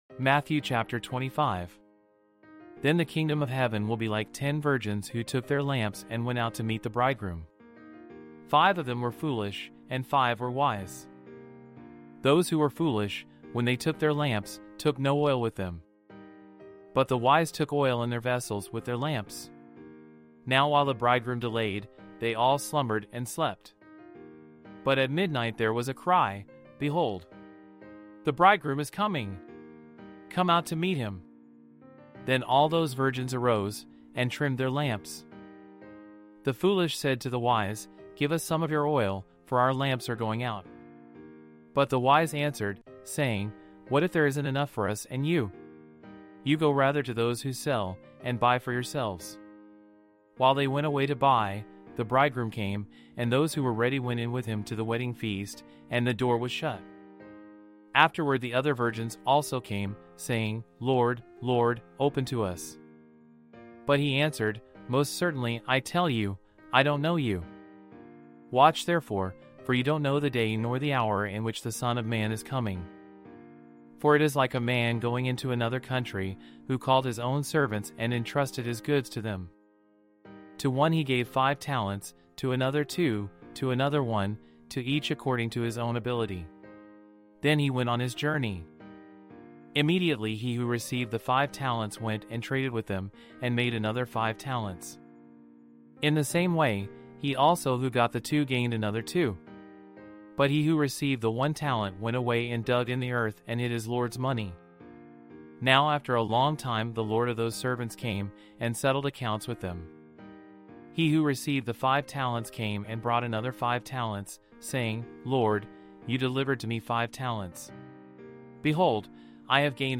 Audio Bible with Text